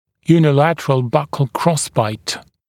[juːnɪ’lætərəl ˈbʌkl ‘krɔsbaɪt][йу:ни’лэтэрэл ˈбакл ‘кросбайт]односторонний боковой перекрестный прикус